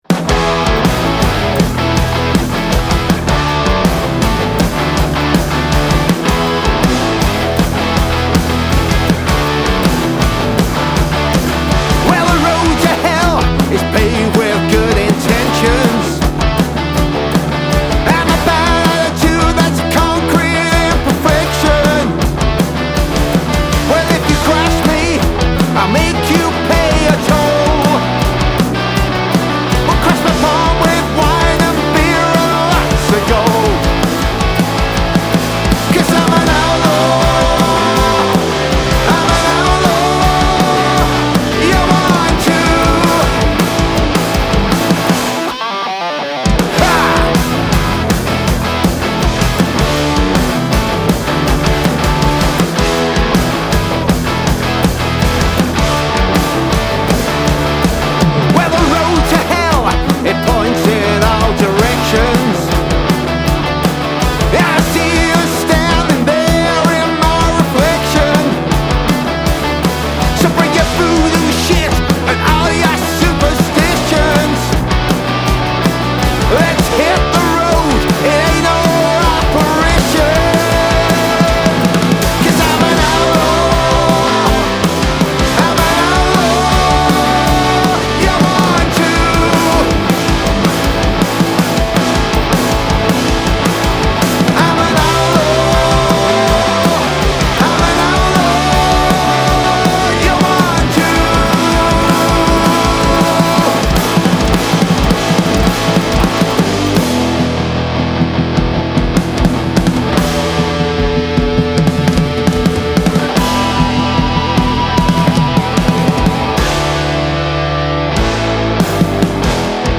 Male Vocal, Guitar, Bass Guitar, Drums